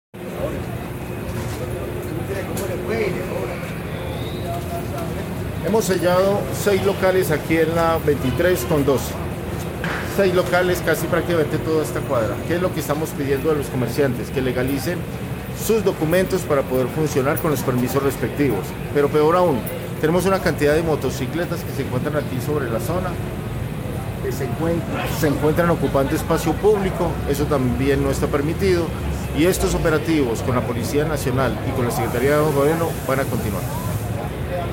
Audio-Secretario-de-gobierno-Jorge-Mario-Trejos-Arias.mp3